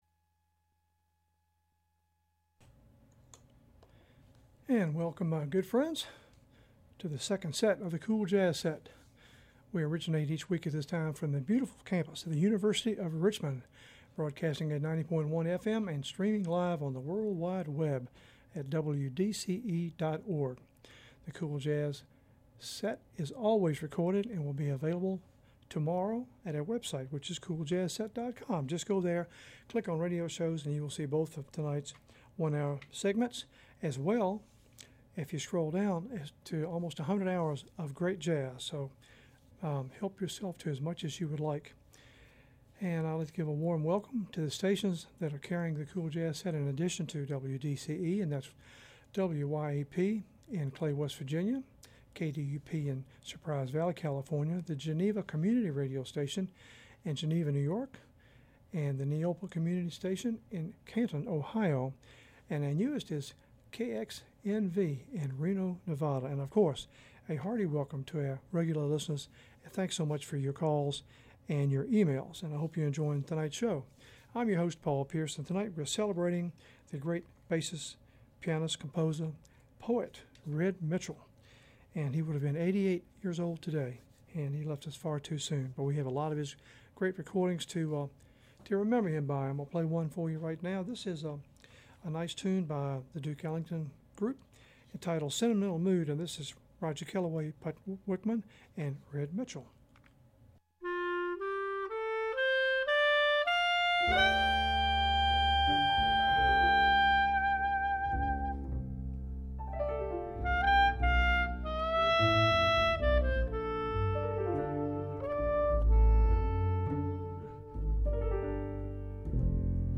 cool jazz set